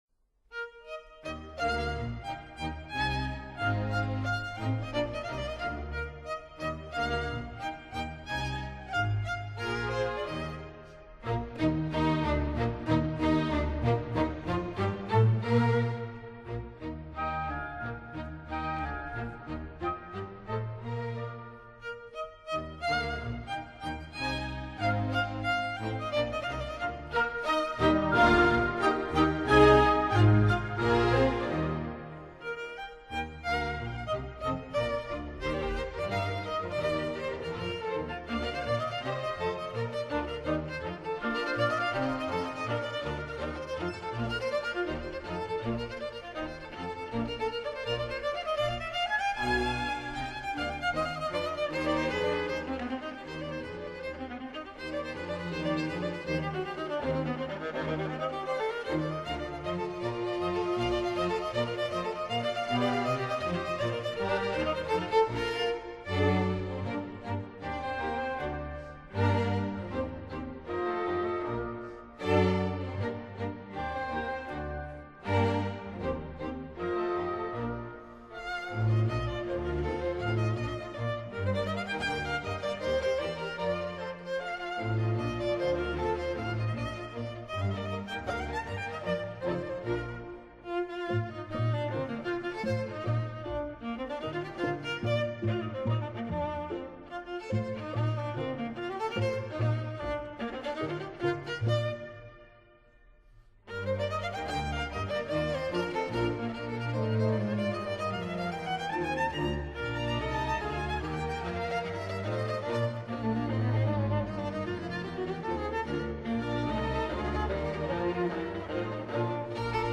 Viola concertos